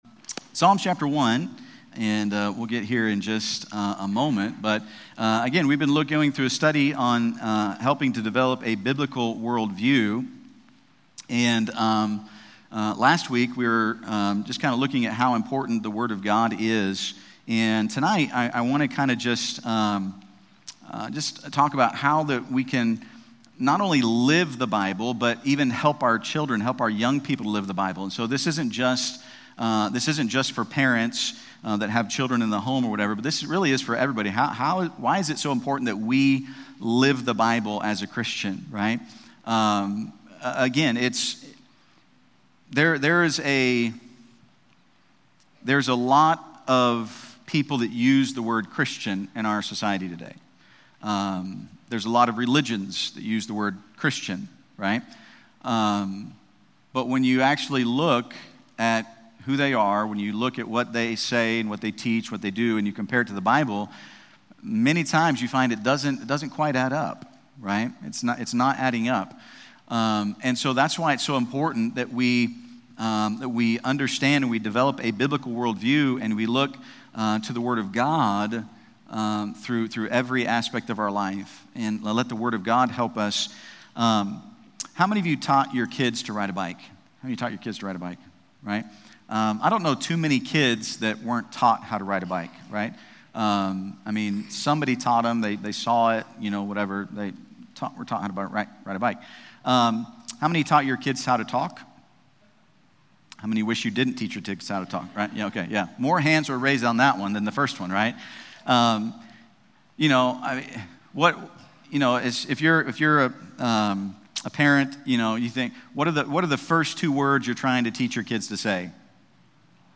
Learn practical steps to make Bible reading a daily habit, hold family Bible time, teach core doctrines, study Scripture, and use tools like commentaries. This sermon emphasizes teaching children to love and apply God’s Word, ensuring it shapes their lives.